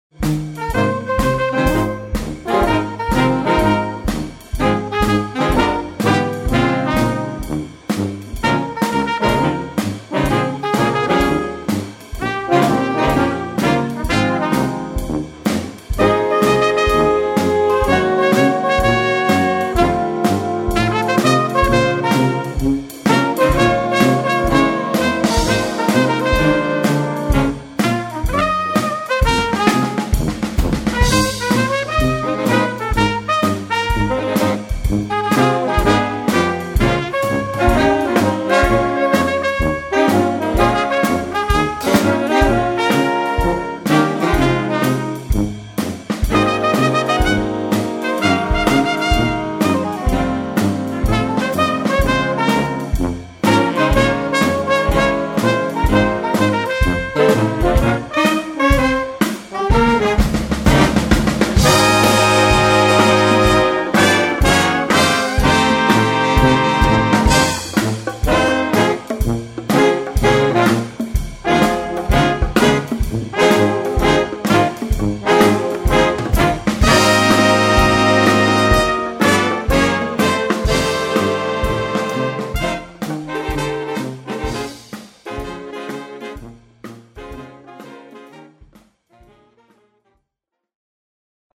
Recueil pour Harmonie/fanfare - Marching Band